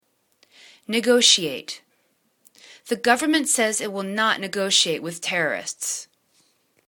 ne.go.ti.ate     /ni'gaw:shia:t/    v